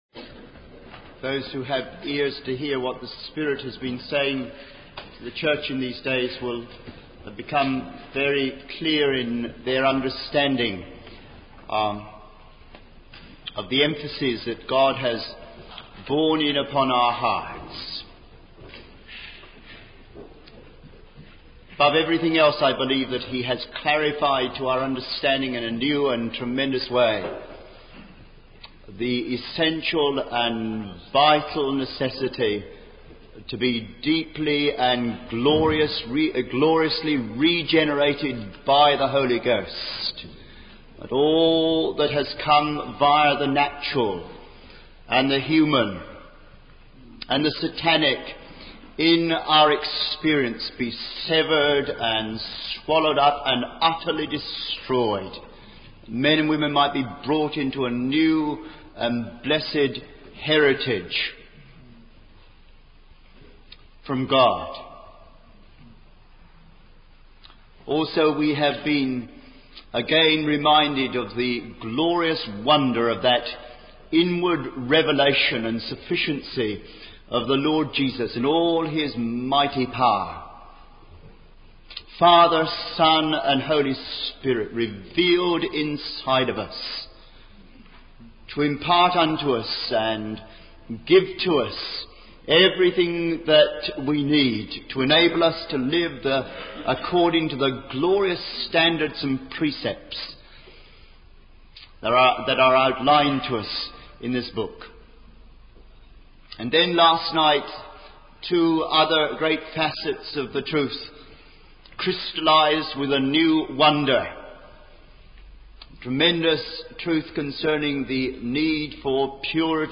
In this sermon, the speaker emphasizes the importance of the word of God as the testimony of the Son of God and the source of resurrection life. The speaker shares testimonies of people experiencing liberation and love through the demonstration of God's love. The sermon also highlights the significance of using spiritual gifts within the context of love, as they can be harmful if used without love.